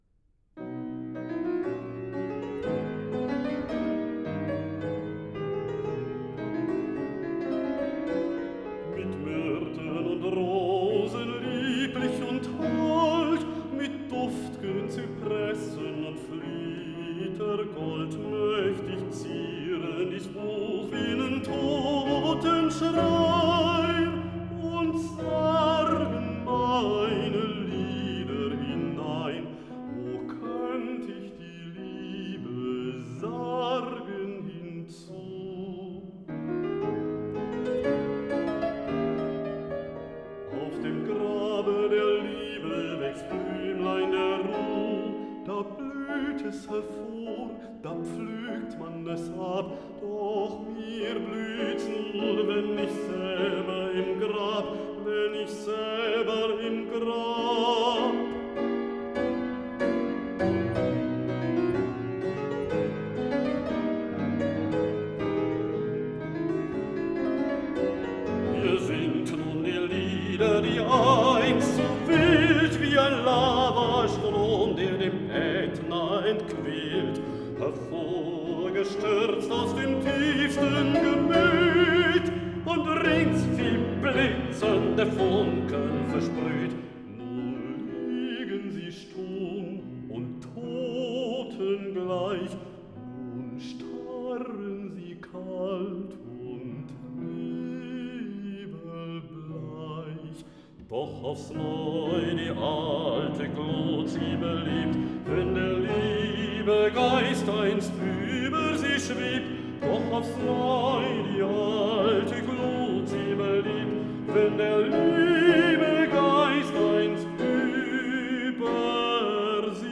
Hammerflügel